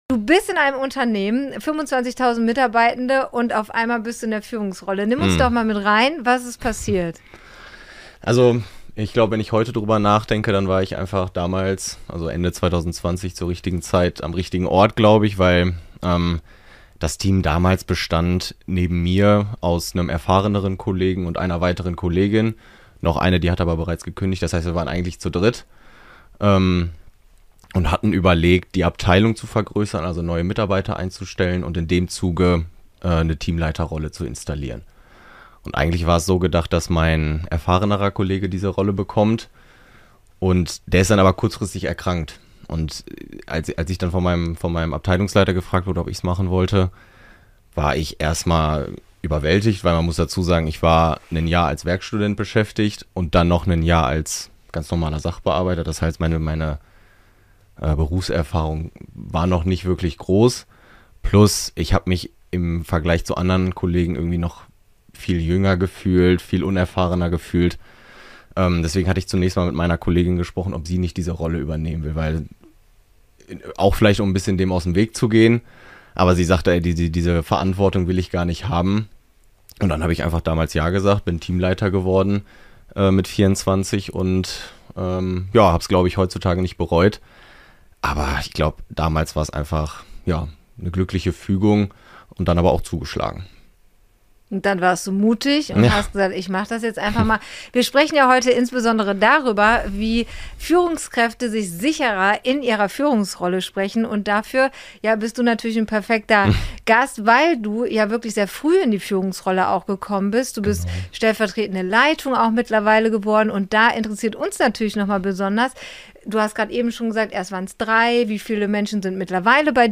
Gedreht wurde im Eventflugzeug auf dem euronova Campus in Hürth.